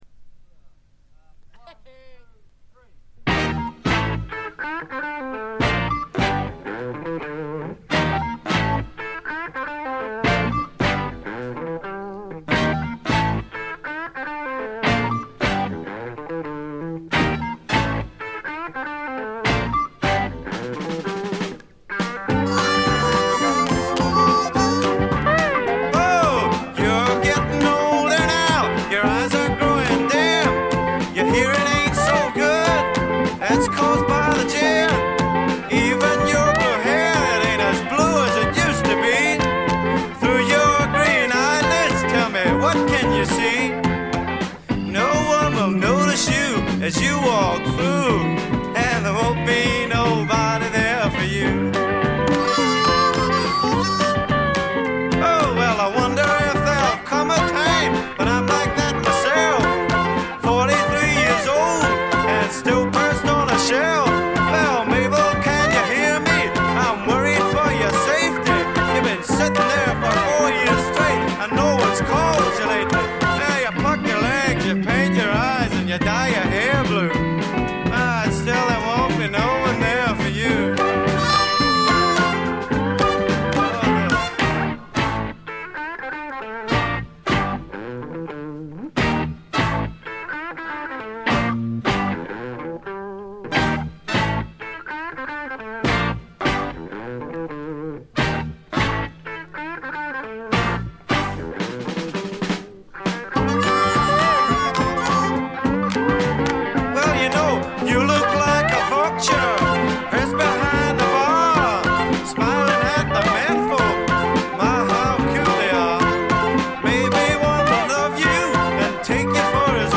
静音部でのわずかなノイズ感程度。
試聴曲は現品からの取り込み音源です。